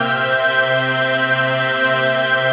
HOUSPAD10.wav